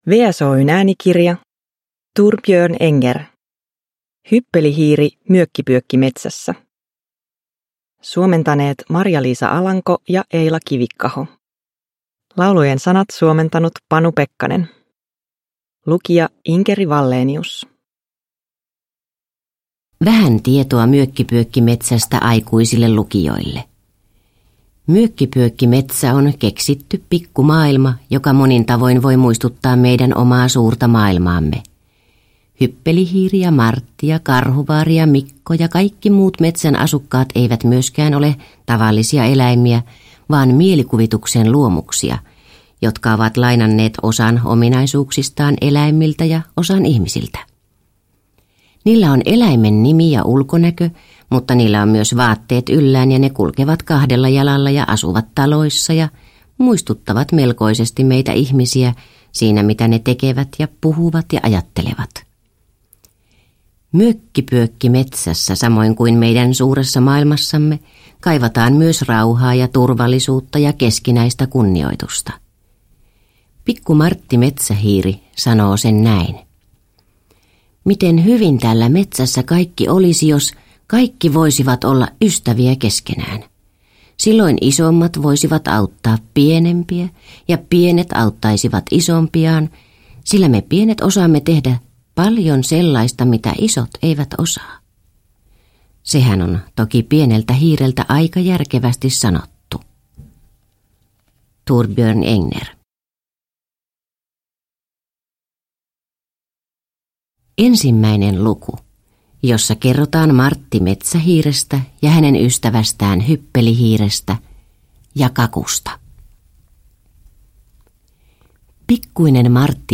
Satuklassikko äänikirjana!